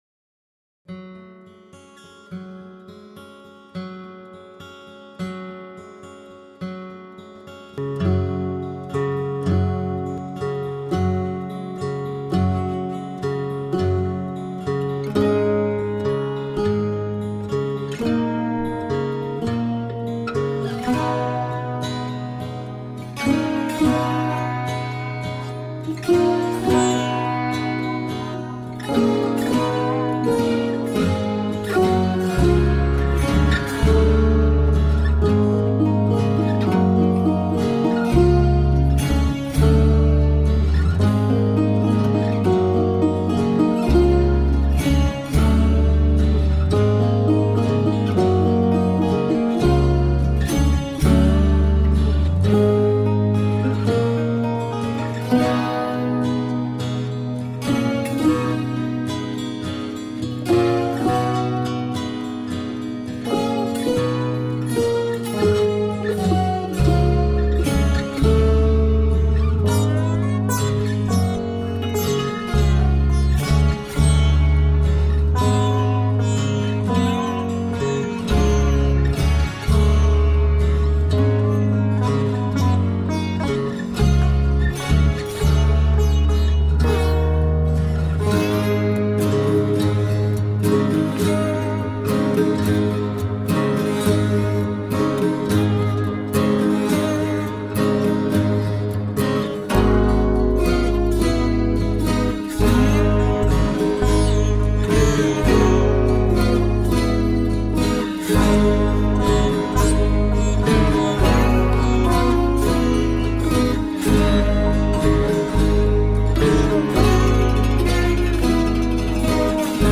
Music played by hand on instruments made of wood.
Tagged as: World, New Age